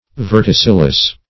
Verticillus \Ver`ti*cil"lus\, n.